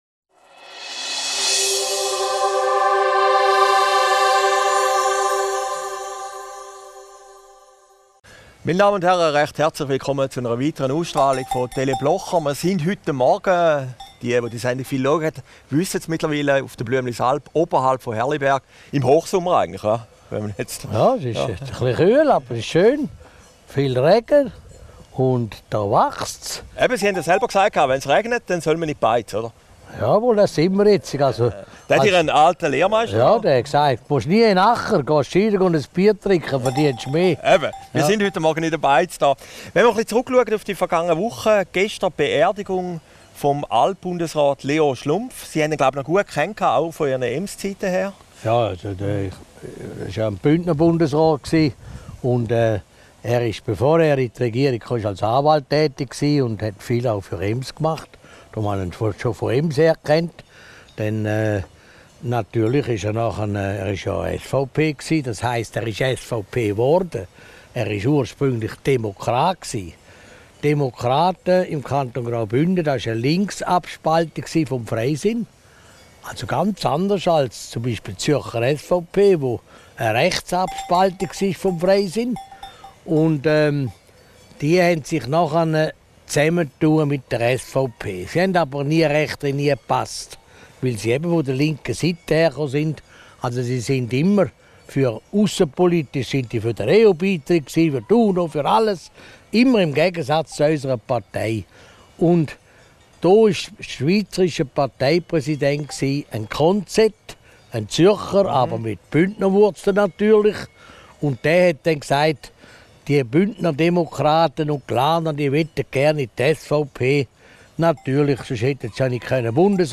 Video downloaden MP3 downloaden Christoph Blocher über Leon Schlumpf, Adolf Ogi und die EMS-Chemie Aufgezeichnet im Rest. Blüemlisalp in Herrliberg, 14.